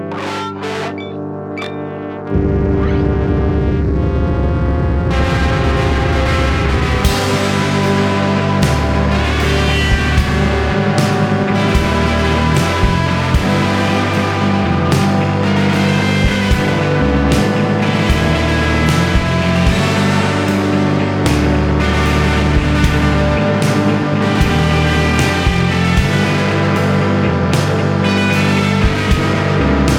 experimental rock duo
guitar